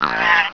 sound / spider / pain1.wav
pain1.wav